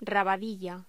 Locución: Rabadilla
voz
locución
Sonidos: Voz humana